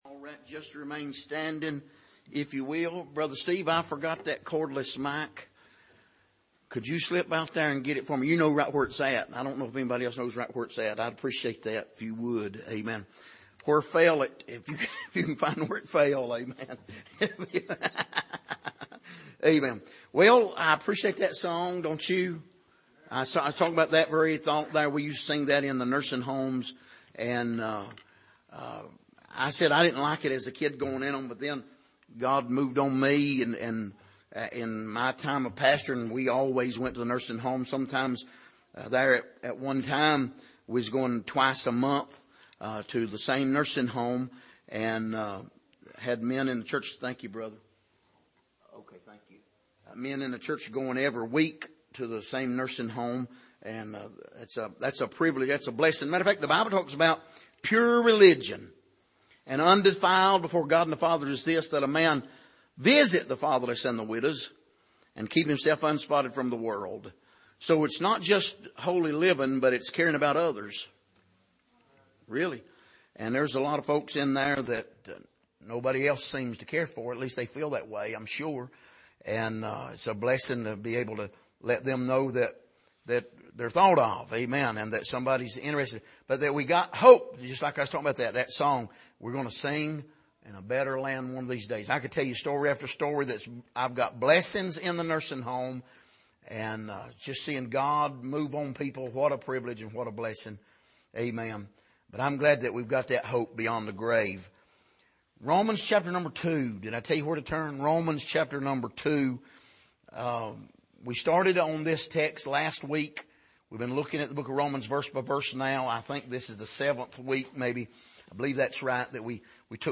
Passage: Romans 2:6-16 Service: Sunday Morning